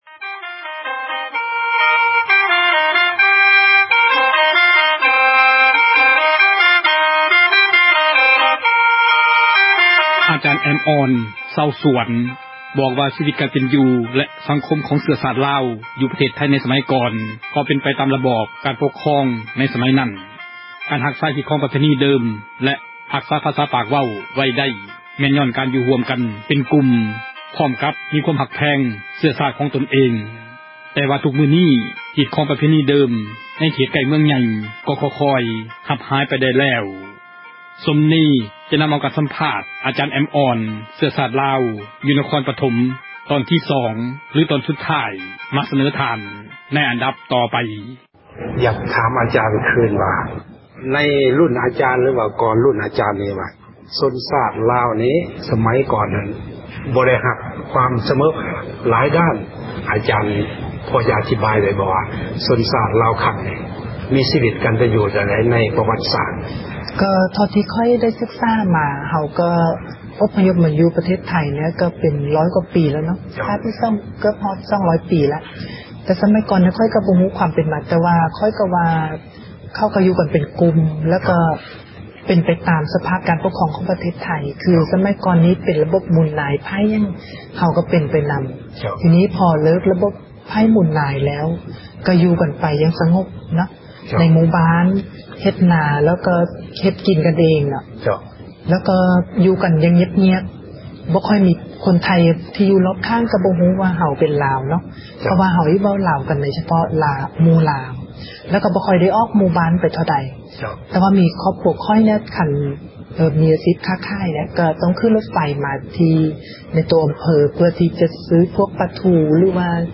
ການໃຫ້ ສັມພາດ